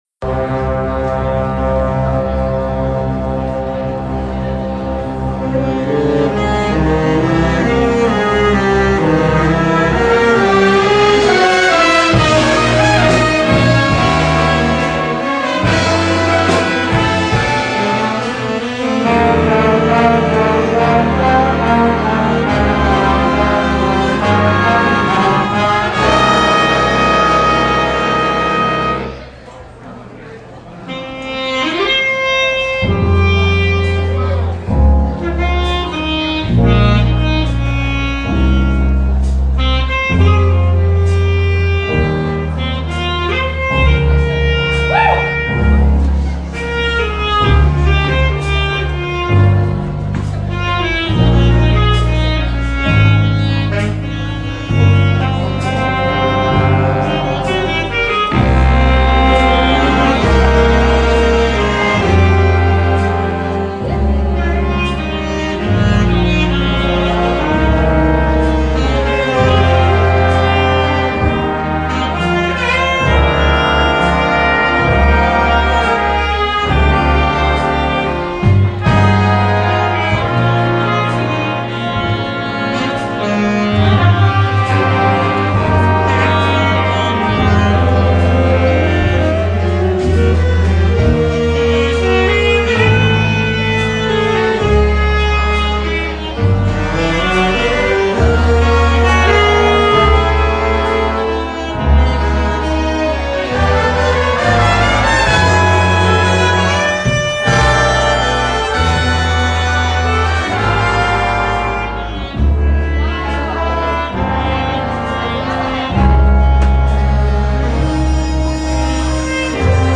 It was a fabulous evening at the Pierz Ballroom as the Pierz Music Department presented their 2nd Annual Jazz Night Fundraiser. This is far from a professional recording. As a matter of fact, I just opened a recording App on my phone and hoped for the best.